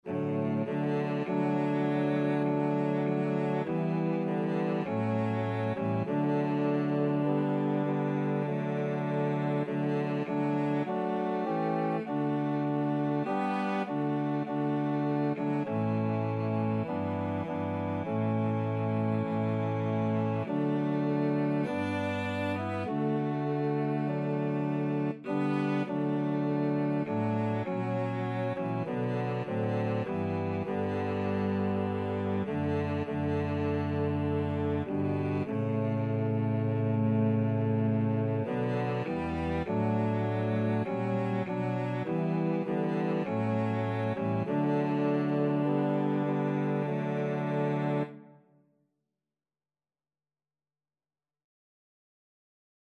4/4 (View more 4/4 Music)
Traditional (View more Traditional Cello Music)